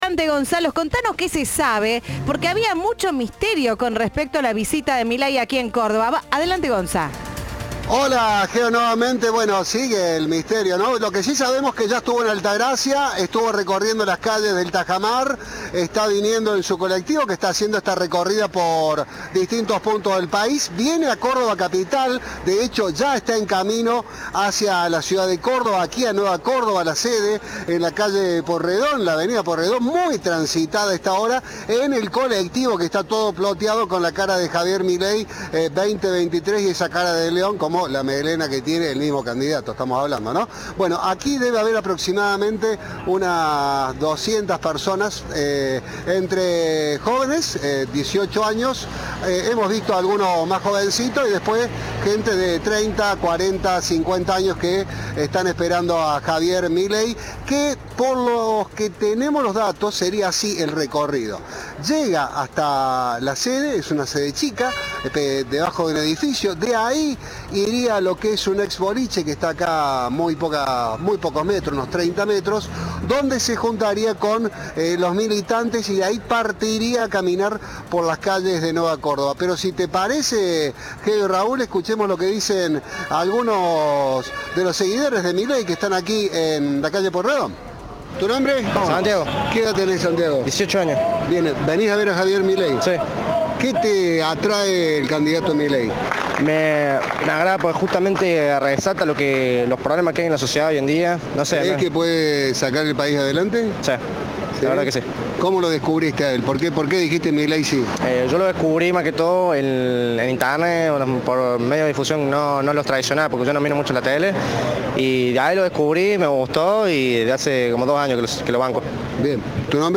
En declaraciones con Cadena 3, el libertario expresó: "Desde Córdoba, viene la revolución liberal. Somos los que más votos vamos a sacar. Vamos a entrar al balotaje y ganar la presidencia".